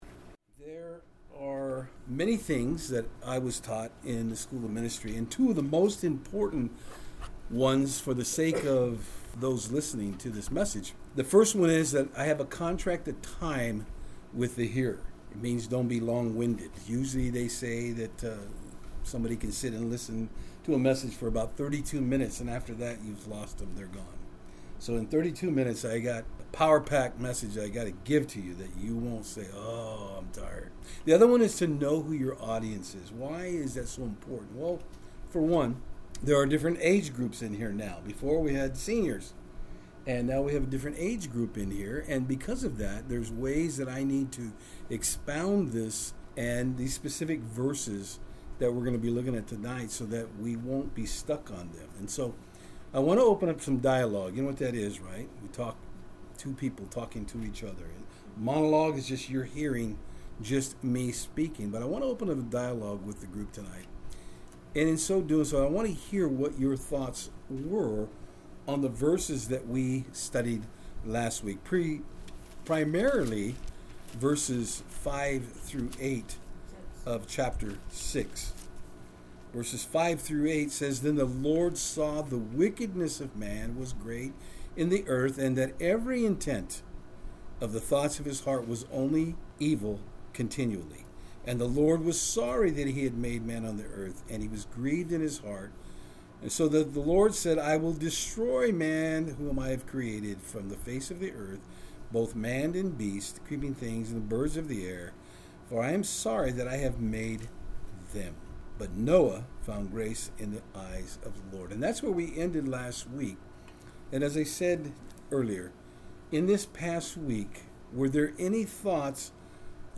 Passage: Genesis 6:5-8; 8:21 Service Type: Saturdays on Fort Hill